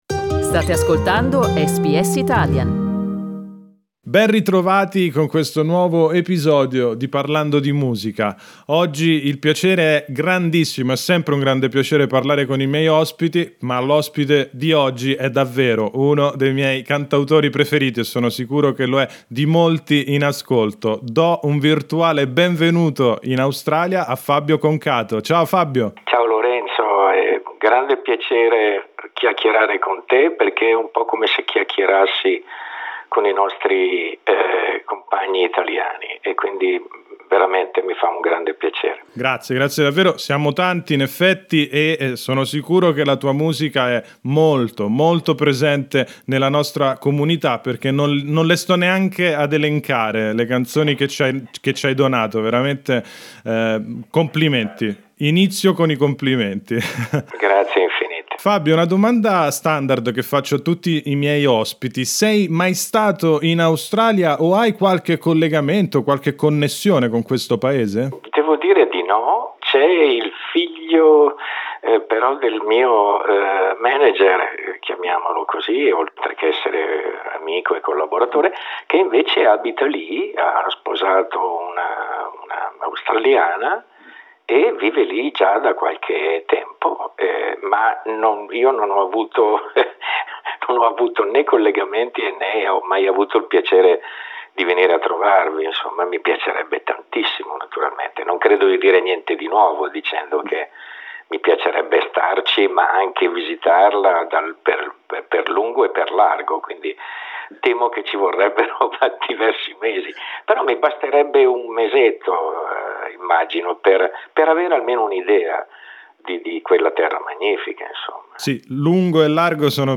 Il cantautore milanese racconta i retroscena della sua carriera e del suo ultimo lavoro, uscito il 12 marzo.